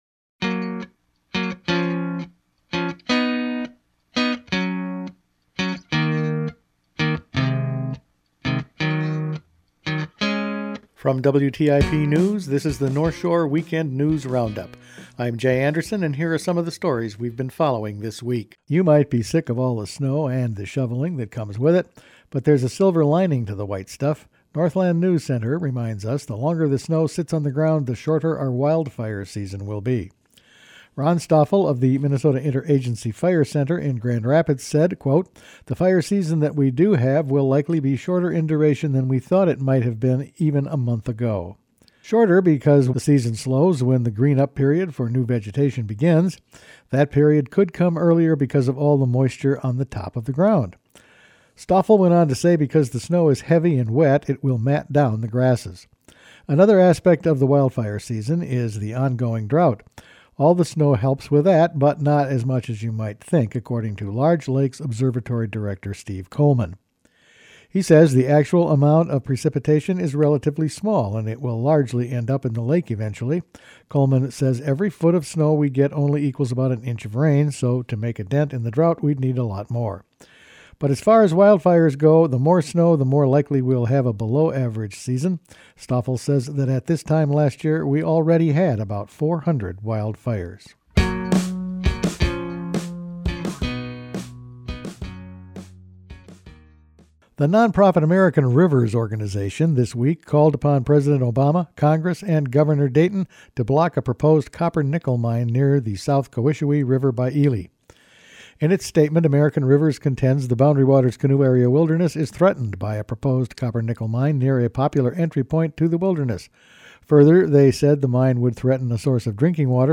Each weekend WTIP news produces a round up of the news stories they’ve been following this week. An endangered river warning was issued this week by a waters watchdog group. The Legislature managed to avoid dealing with wolf trapping and the long winter may help shorten the wildfire season.…all in this week’s news.